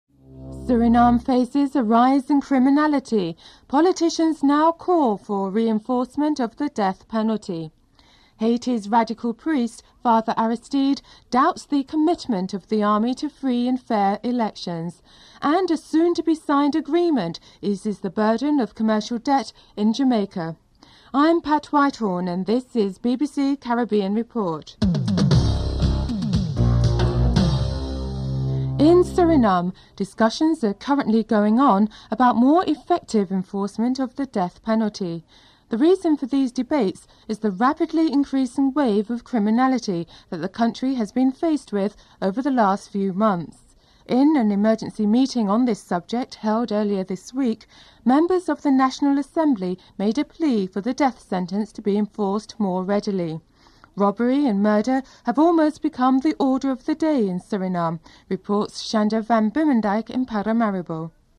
1. Headlines (00:00-00:28)
Interview with G. Arthur Brown, Governor of the bank of Jamaica (07:55-10:41)